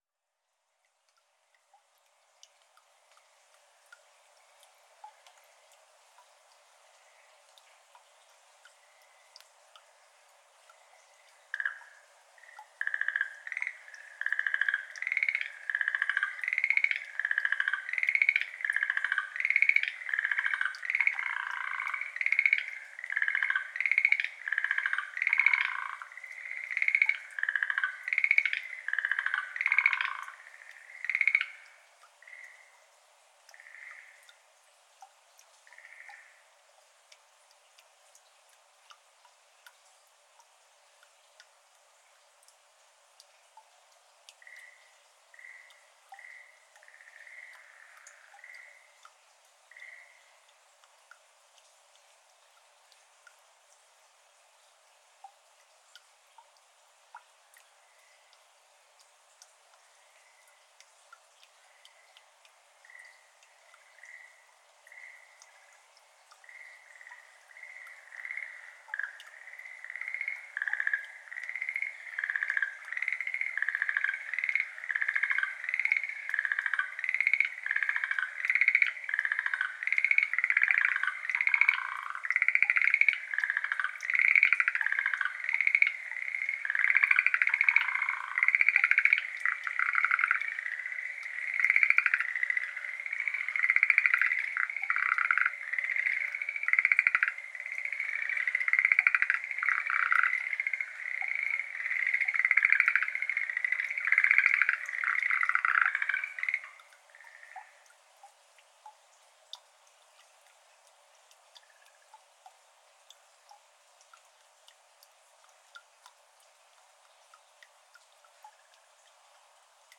水音とシュレーゲルアオガエルA 06:53pm(HPLバイノーラル)